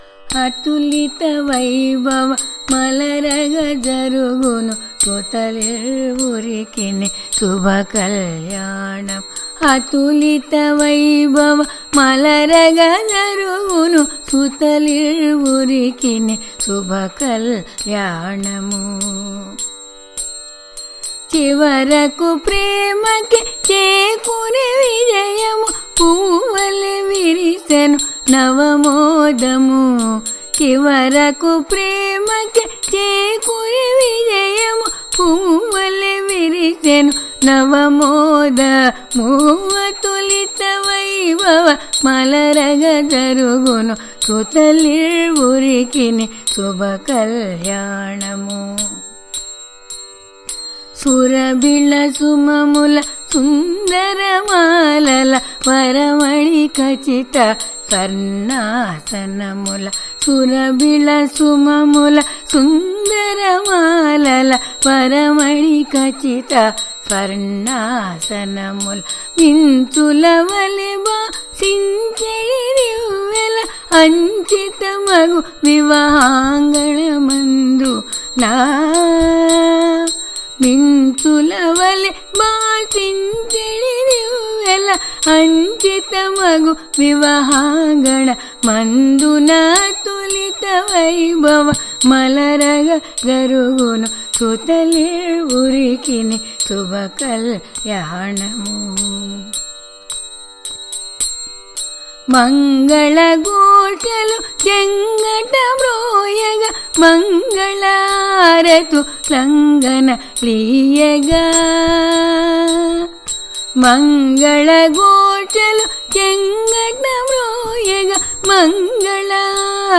(పాత్రధారులందఱును గలిసి పాడుదురు)